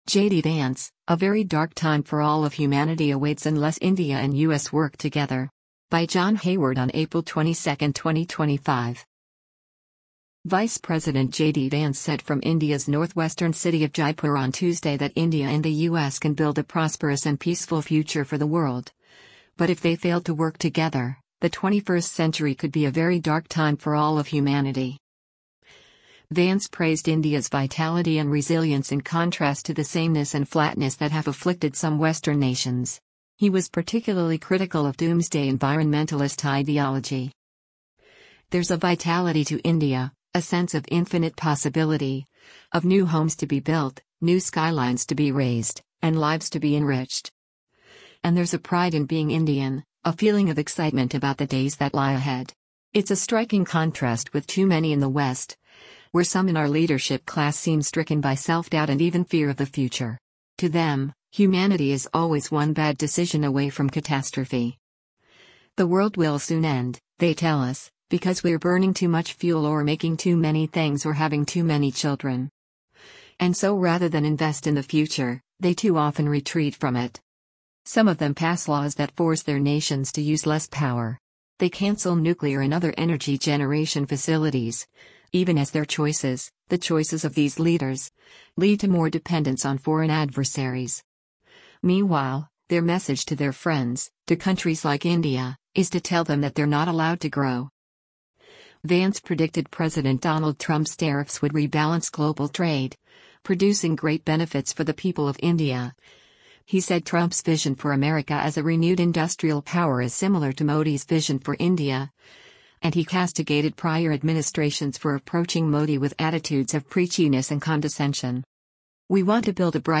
US Vice President JD Vance speaks at the Rajasthan International Centre in Jaipur, India,